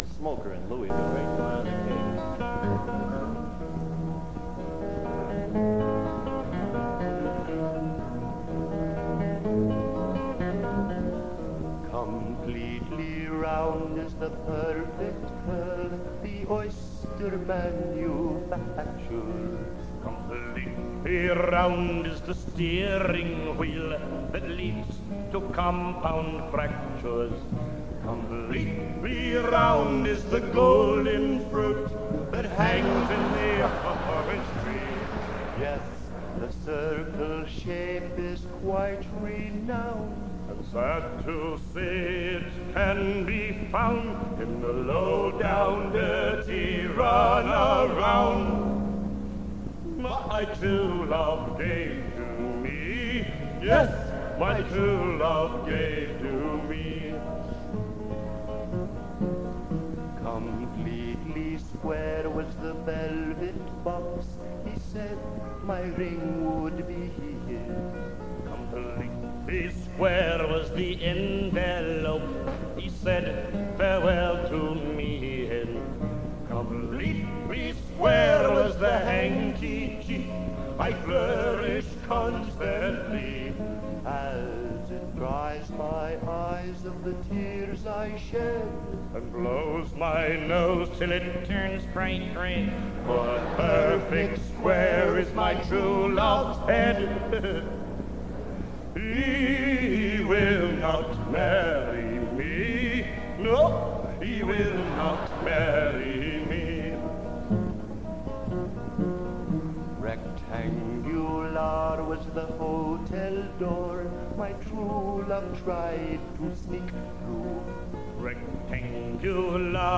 This is a bootleg of a concert from sometime in 1963.